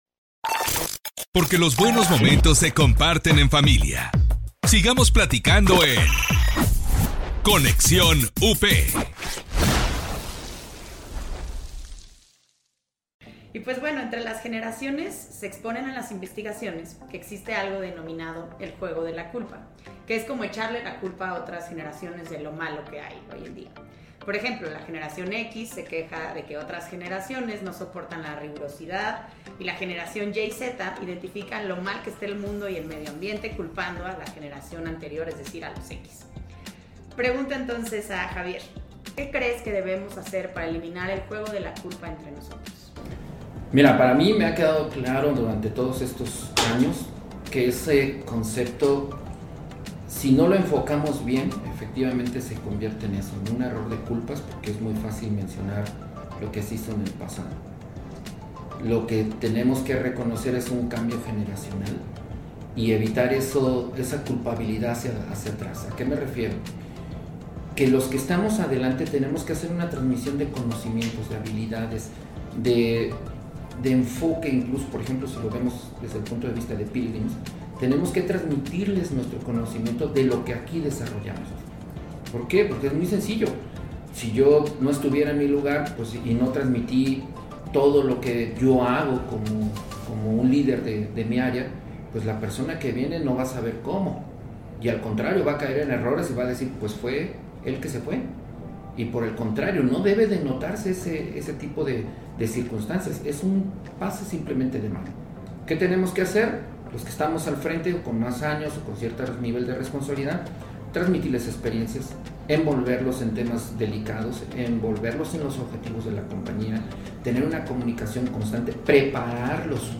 En este episodio reunimos a tres invitados representando a varias generaciones que pertenecen a la Gran Familia Pilgrim's.